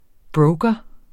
Udtale [ ˈbɹɔwgʌ ]